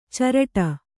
♪ caraṭa